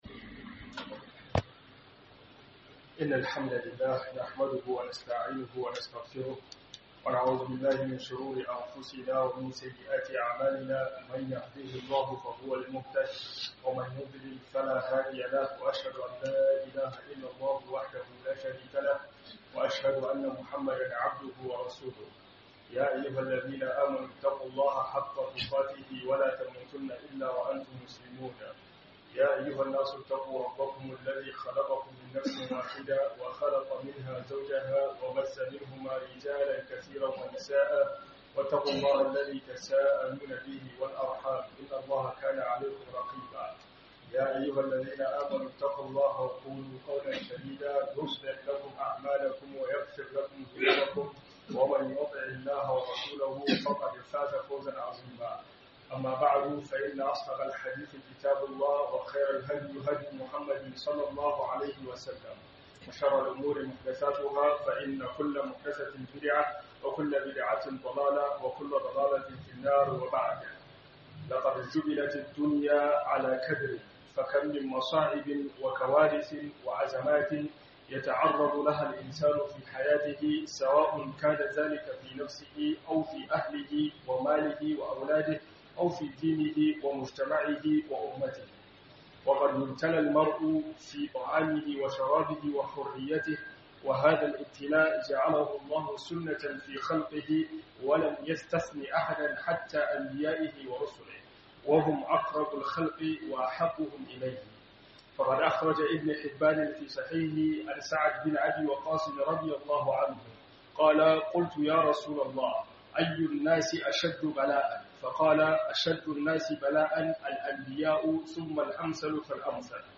Ib'tila'i - HUDUBA